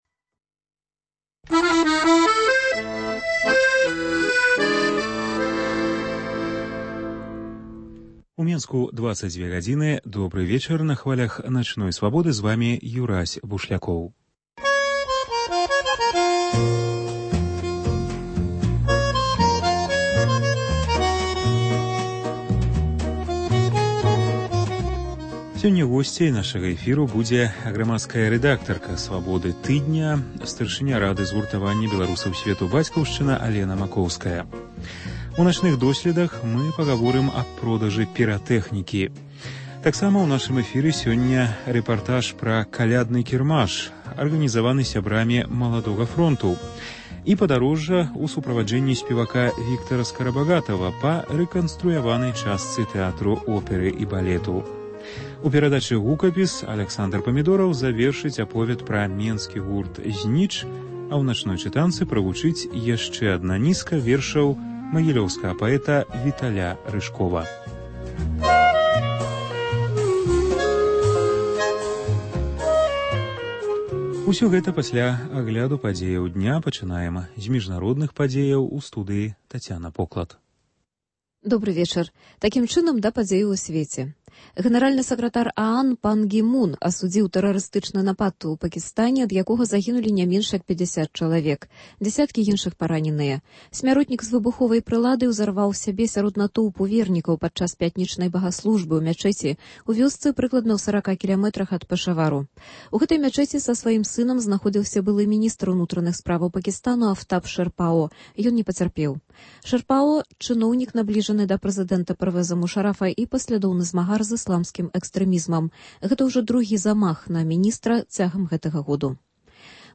У “Начных досьледах” мы пагаворым пра забарону піратэхнікі. У нашым апытаньні гарадзенцы скажуць, як, на іх думку, трэба рэгуляваць выкарыстаньне піратэхнікі.